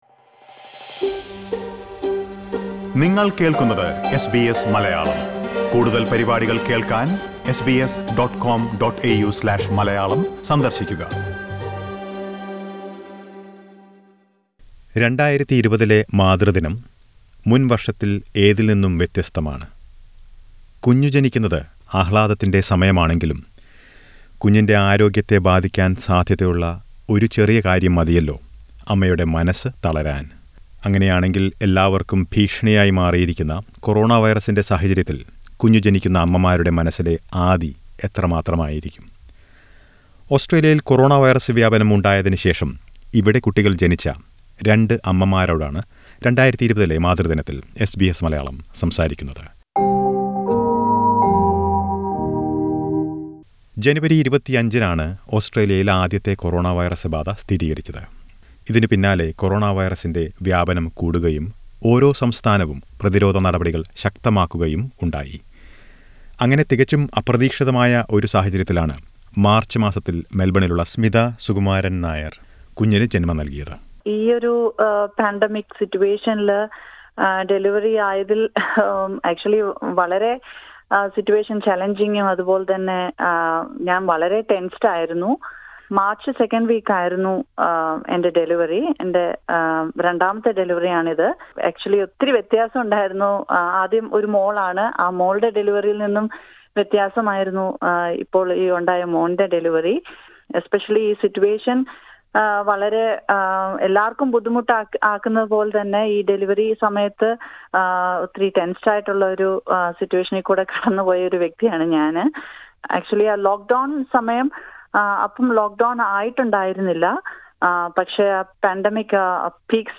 കൊറോണവൈറസ്‌ ബാധക്കിടെ ഗർഭകാലം കഴിച്ചുകൂട്ടിയവർക്ക് ഒട്ടേറെ ആശങ്കകളാണ് ഉണ്ടായിരുന്നത്. ഇത്തരം ആശങ്കകളിലൂടെ കടന്നുപോയ രണ്ടു ഓസ്‌ട്രേലിയൻ മലയാളികളോട് ഈ മാതൃദിനത്തിൽ എസ് ബി എസ് മലയാളം സംസാരിച്ചു.